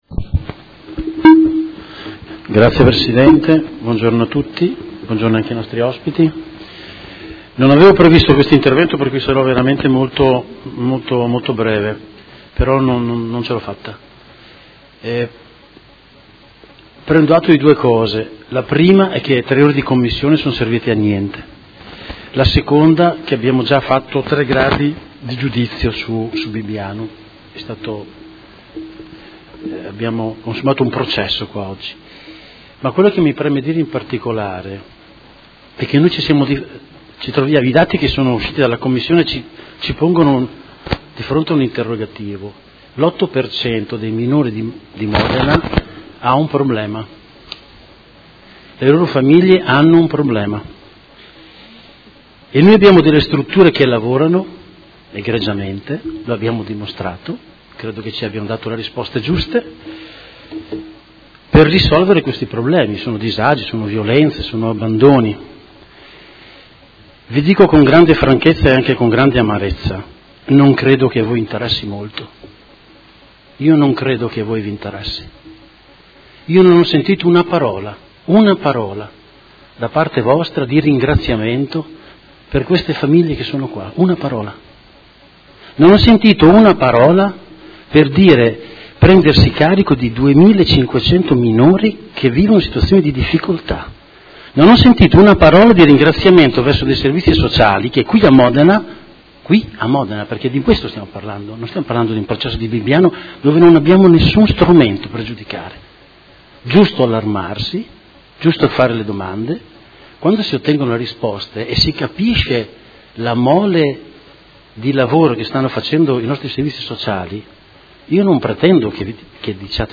Seduta del 26/09/2019. Dibattito su Interrogazione del Gruppo Consiliare Lega Modena avente per oggetto: Tutela dei minori in affido, Interrogazione del Consigliere Rossini (FDI-PDF) avente per oggetto: Affidamento dei minori, Interrogazione del Gruppo Consiliare M5S avente per oggetto: Inchiesta Angeli e Demoni e situazione modenese e Interrogazione del Consigliere Bertoldi (Lega Modena) avente per oggetto: Chiarimenti sulla Determinazione n. 1386/2019 del 03/07/2019